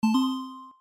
ピコン音
フリー音源効果音「ピコン音」です。
pikon.mp3